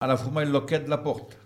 Localisation Saint-Jean-de-Monts
Enquête Arexcpo en Vendée
Catégorie Locution